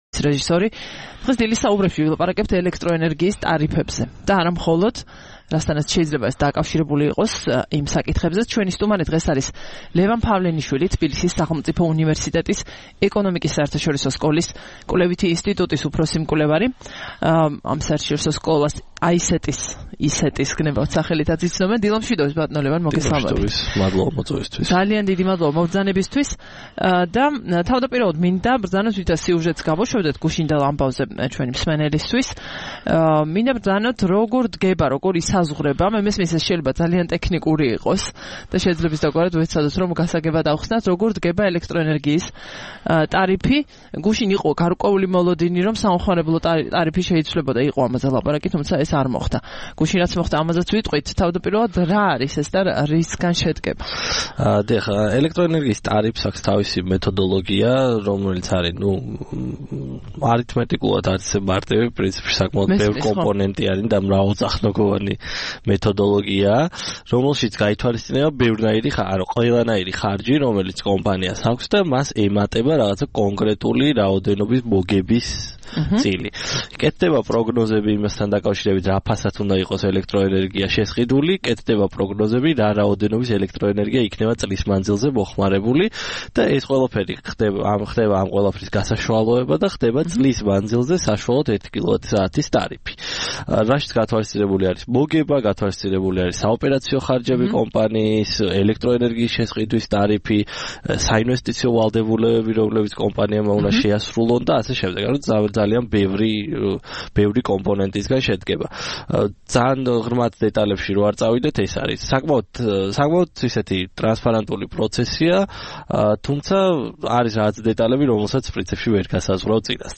27 დეკემბერს რადიო თავისუფლების "დილის საუბრების" სტუმარი იყო